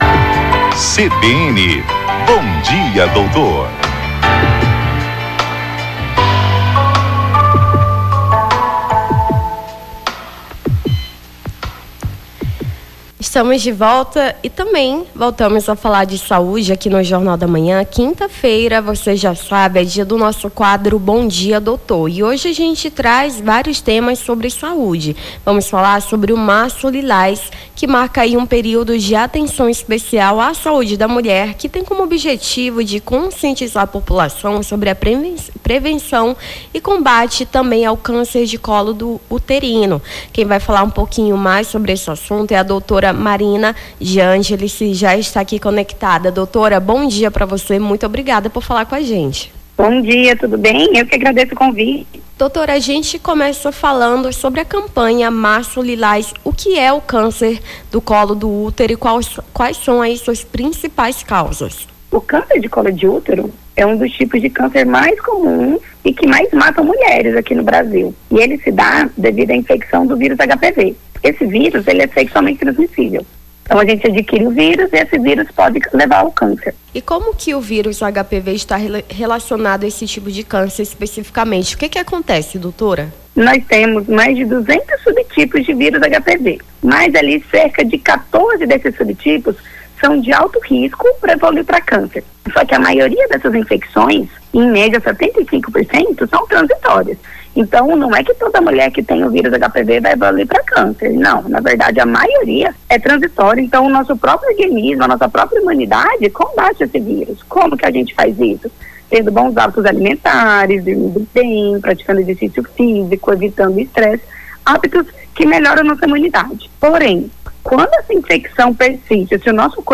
Bom dia, Doutor: ginecologista fala sobre a campanha Março Lilás